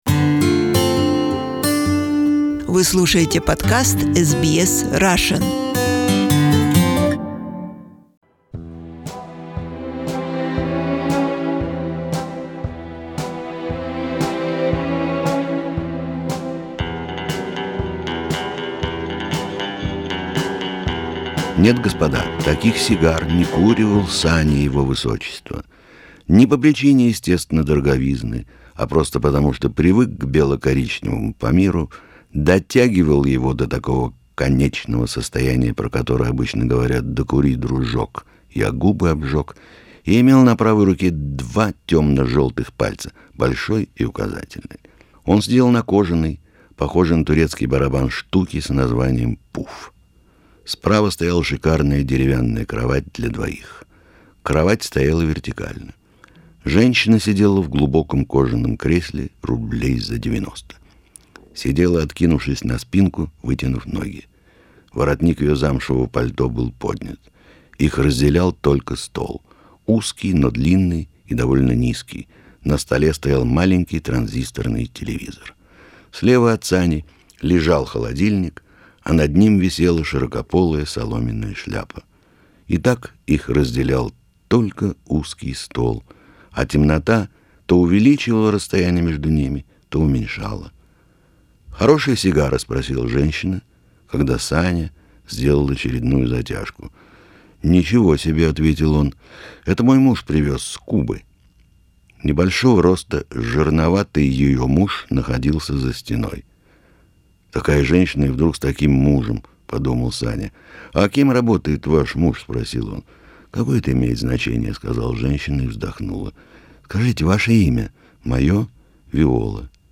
During one of his visits, in 2002, we taped Arkady Arkanov reading three of his stories which we produced and later aired several times. And as often with Arkanov, they are far from just funny stories, in fact, they quite sad and philosophical miniatures.